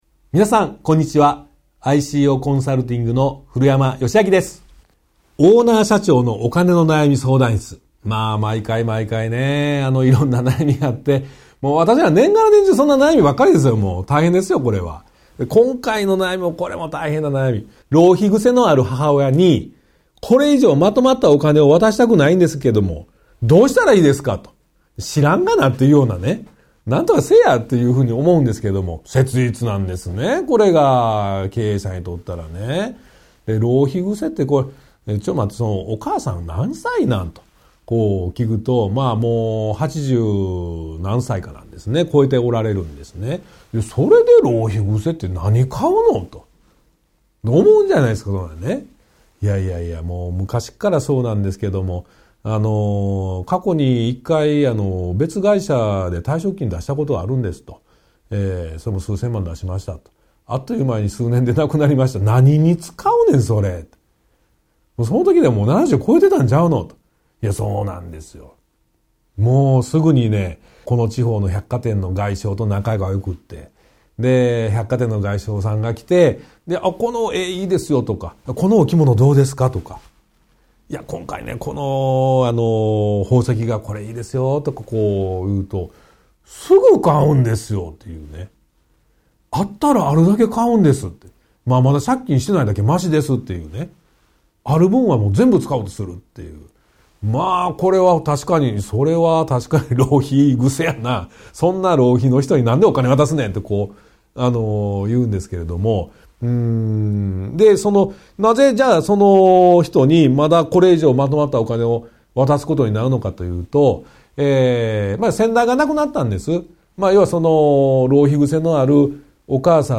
ワンポイント音声講座 相談4：浪費癖のある母親に、これ以上まとまったお金を渡したくないのですが、どうしたらよいでしょうか。